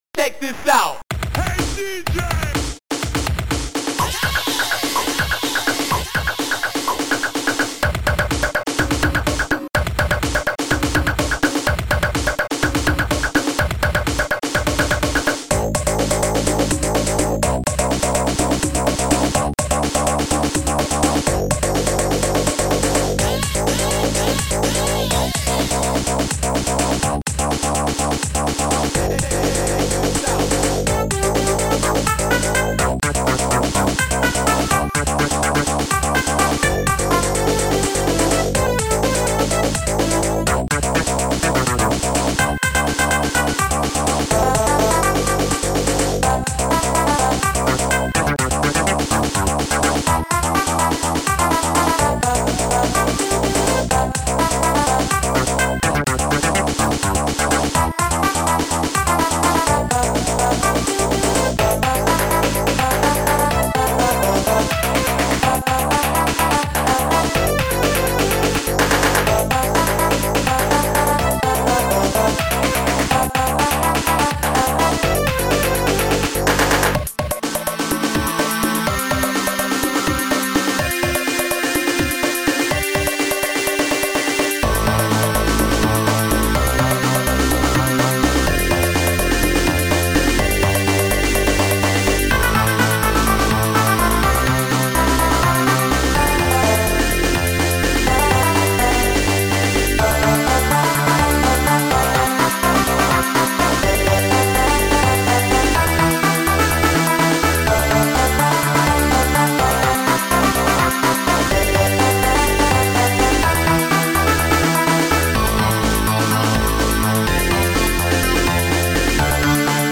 cool acid tune